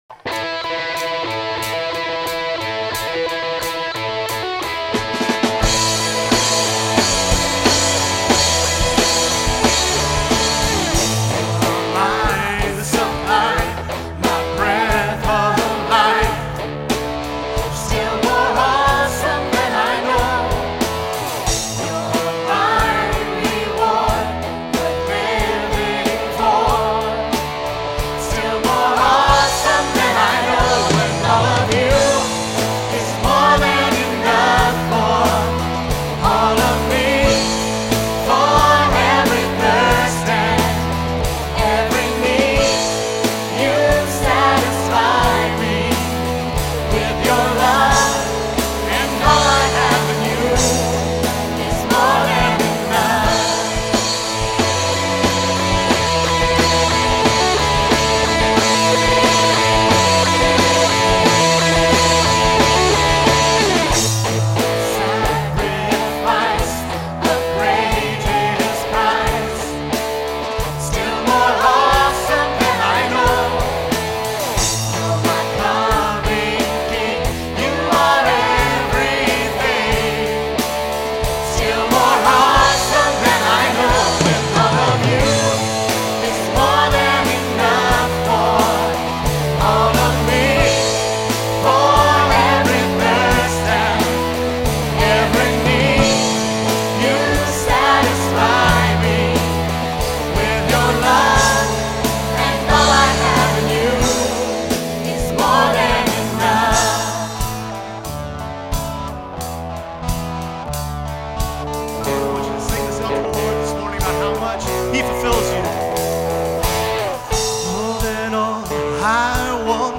1. The sound mix will not always be well balanced. The recordings were taken off the "house mix", which means that adjustments made for "stage volume" and "room EQ" have an impact on the recording. Unfortunately, that often means the bass gets turned down as a result of someone having the bass too high in their monitor, or any other random boominess that shows up.
2. You often get imprecise starts and stops to the songs as that is dependent upon the sound man hitting the button to tell the recorder to start a new track. We often go from one song right into another, so this can be a difficult task.